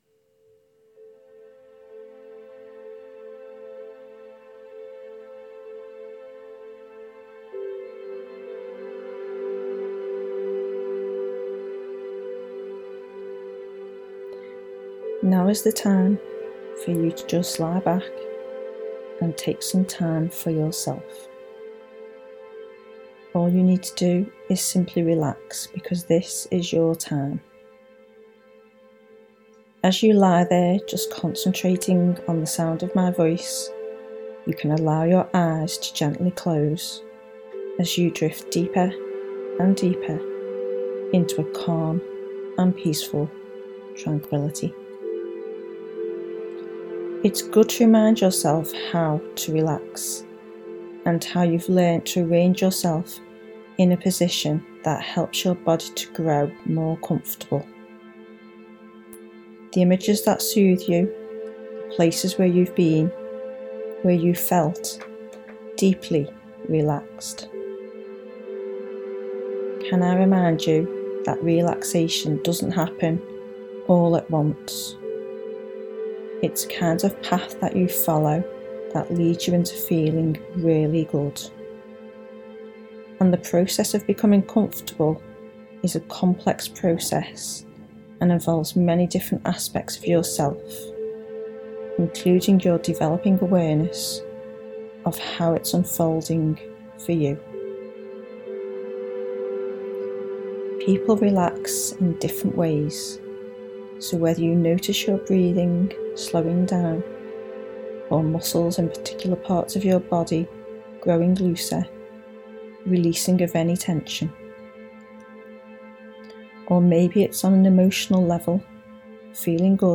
Hypnotic relaxation mp3
This guided relaxation track is free to use, you can listen to it directly from this webpage. The best time to listen is before you go to sleep, it will help you relax and help you improve your sleep.